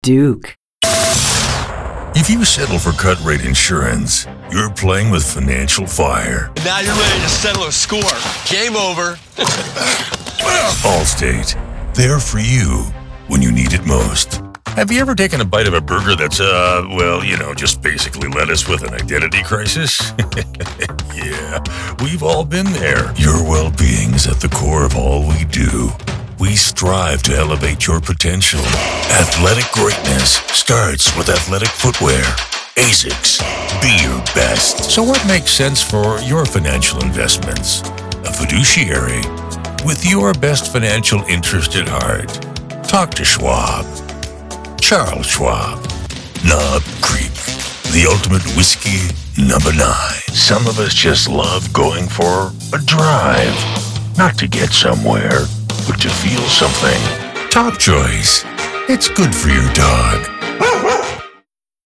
those super DEEP tones that will make your spot sing.
Showcase Demo